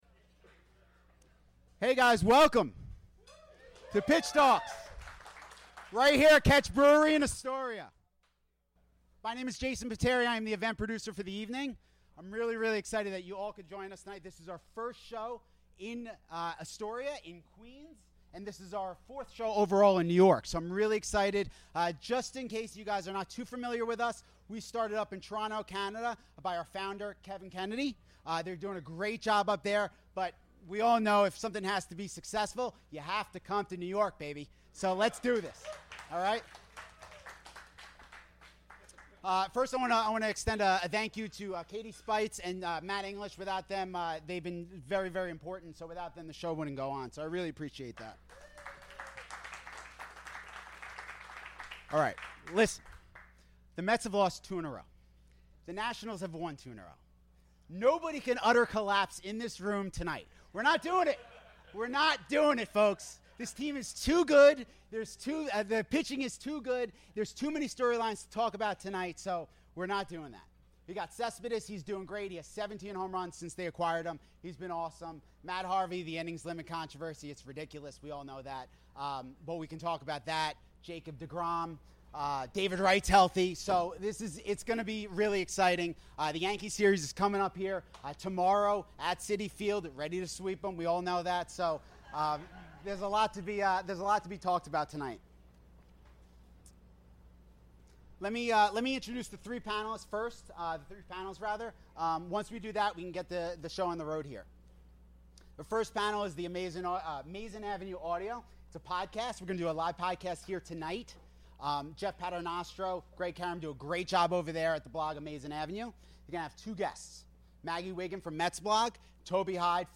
Episode 139: Live at PitchTalks
In a special live edition from PitchTalks at Katch Brewery in Astoria, Queens